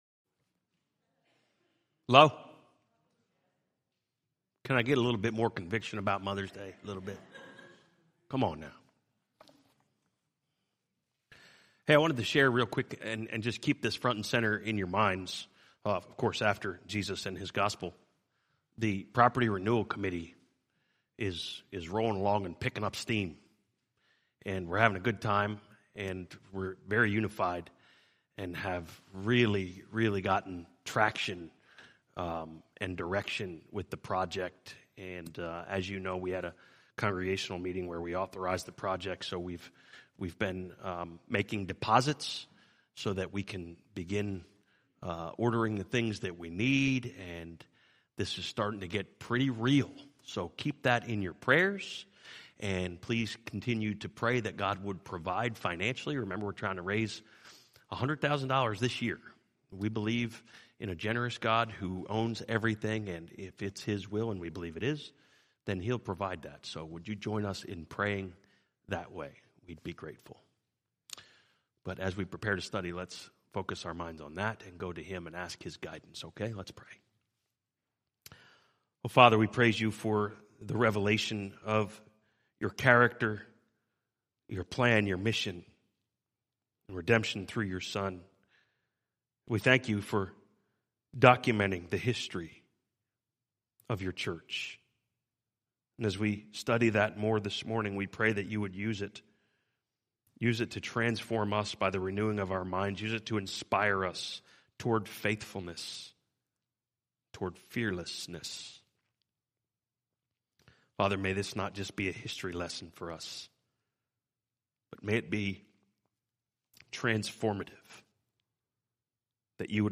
Message: